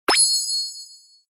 Download Free Magical Sound Effects
Download Magical sound effect for free.
Magical